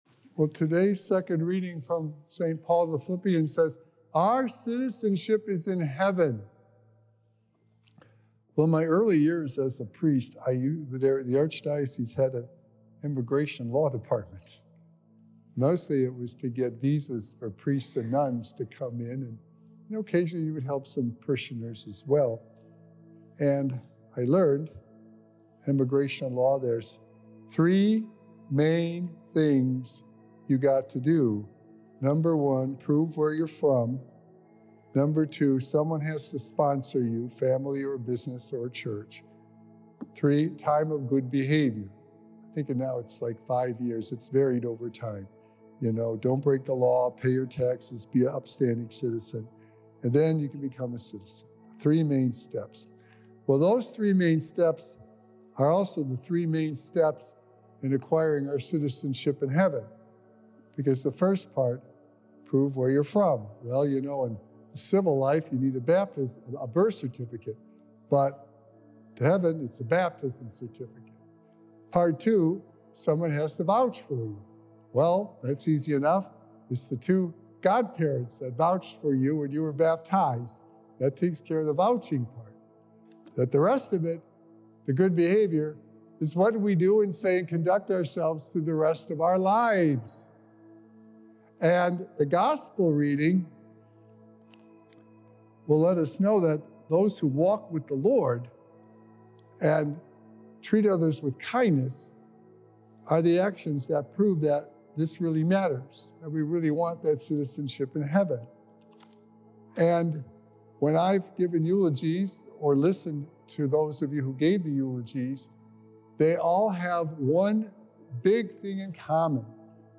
Sacred Echoes - Weekly Homilies Revealed
Recorded Live on November 4th, 2024 at St. Malachy Catholic Church.